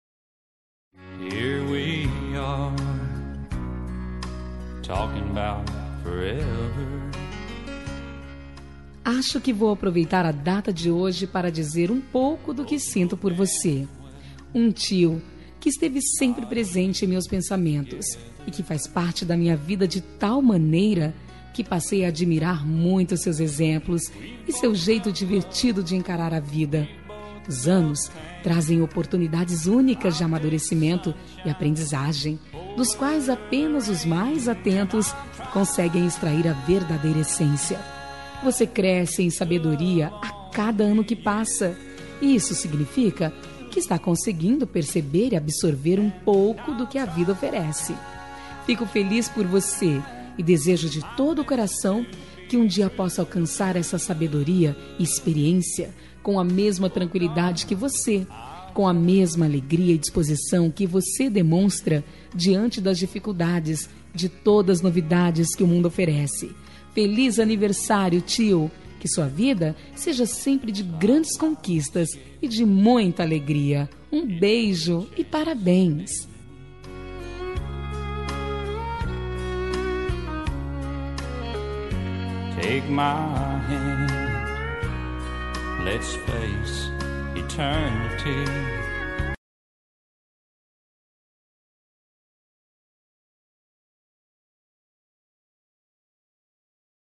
Aniversário de Tio – Voz Feminina – Cód: 928